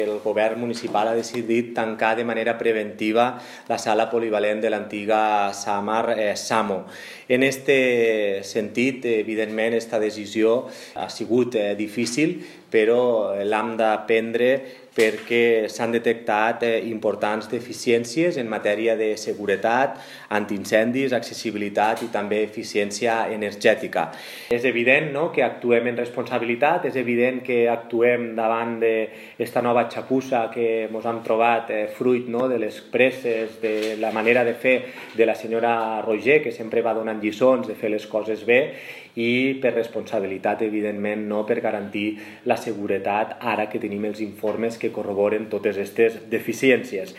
L’alcalde de Tortosa, Jordi Jordan, ha assegurat que es tracta d’una decisió difícil però responsable alhor que ha qualificat de nyap les reformes fetes per l’anterior govern de Junys i…